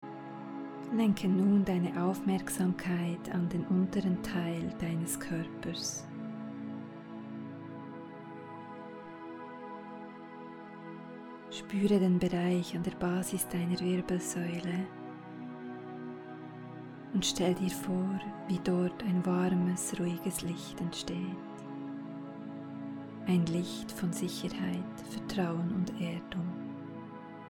Geführte Chakren Meditation MP3
hoerprobe-meditation-harmonisierung.mp3